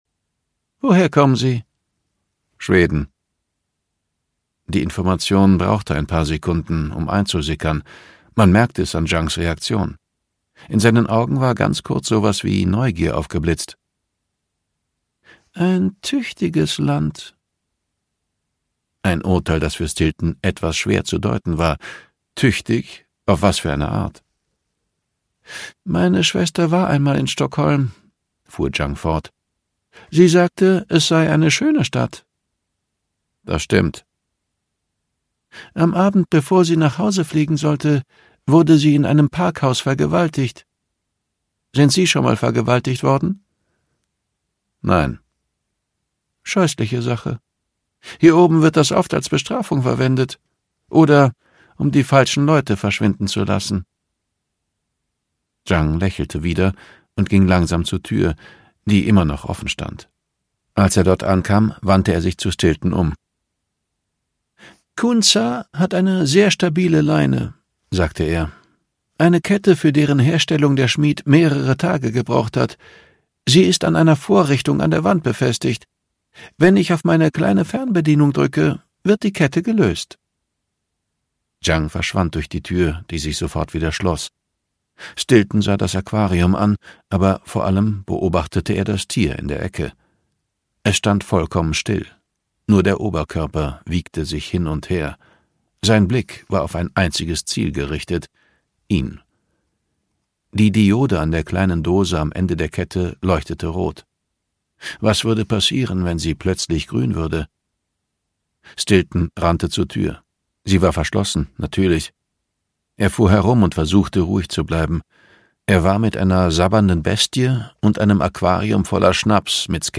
Vollständige Lesung